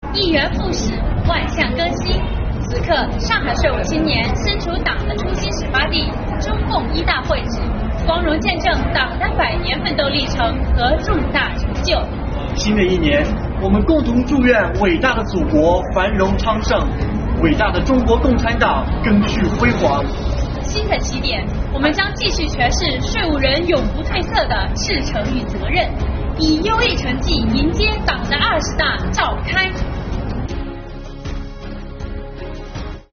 在上海，国家税务总局上海市税务局干部来到中共一大会址，重温党的百年辉煌，赓续红色革命精神，共同祝愿祖国繁荣昌盛。他们表示，新的一年，将继续诠释税务人的赤诚和责任，以优异成绩迎接党的二十大召开。